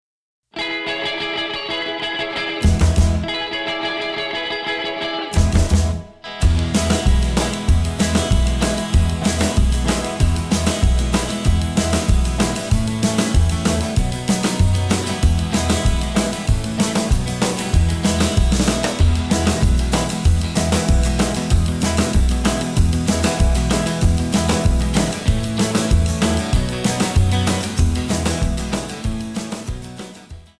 Tonart:E ohne Chor
Die besten Playbacks Instrumentals und Karaoke Versionen .